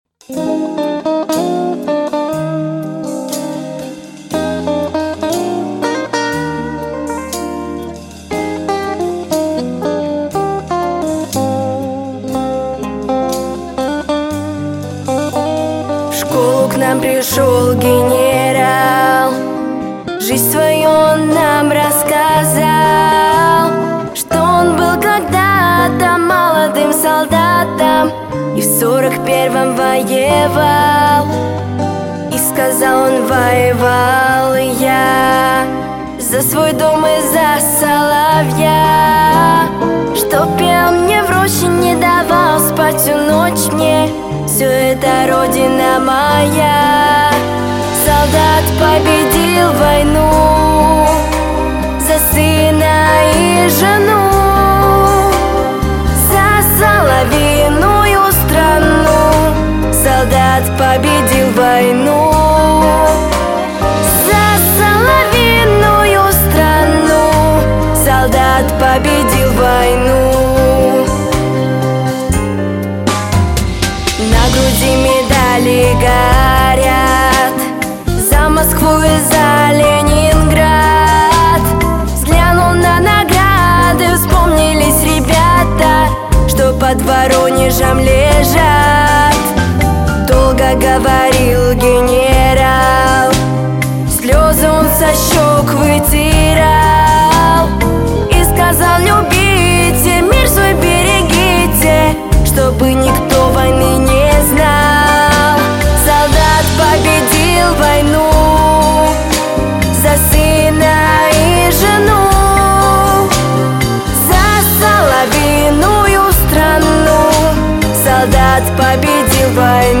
праздничная песня